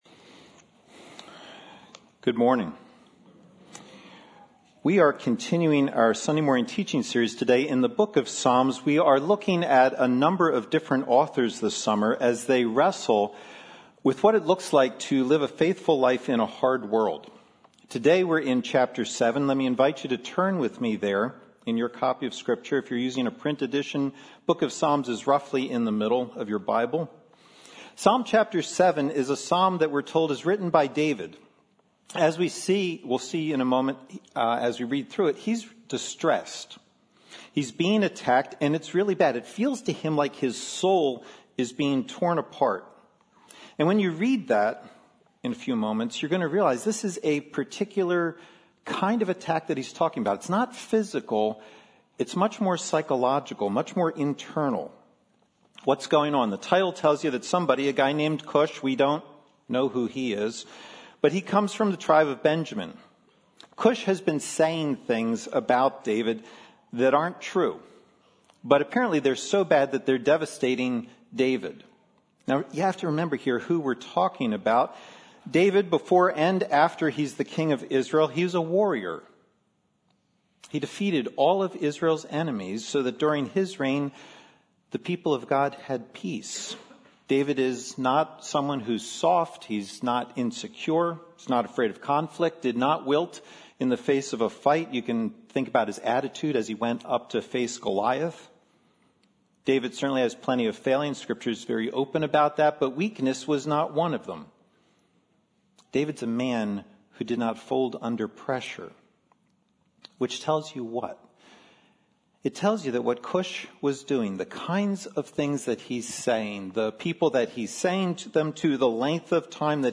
This is the RSS feed for Sunday sermons from New Life Presbyterian Church, Glenside, PA.